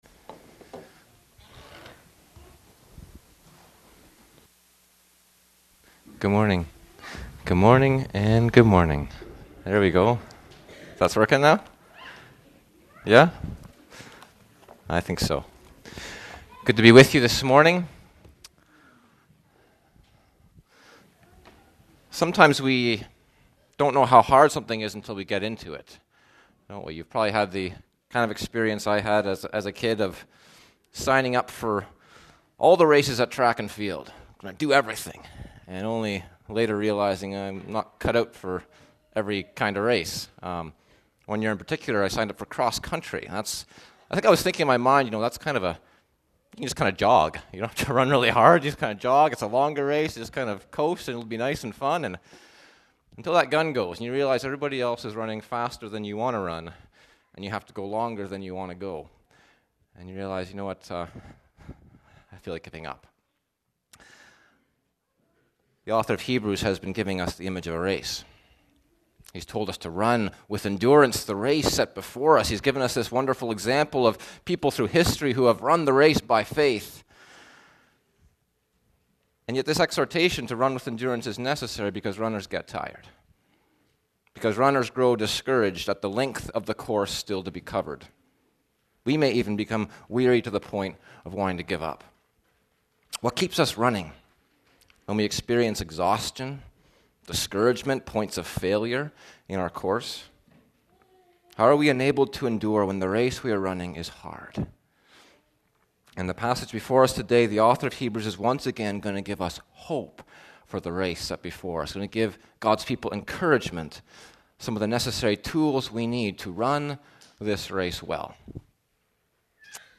Feb. 16, 2020 – “An Anchor for the Soul” – Hebrews 12:3-17 – Running Well Feb. 16, 2020 – “An Anchor for the Soul” – Hebrews 12:3-17 – Running Well Download Posted in Sermons .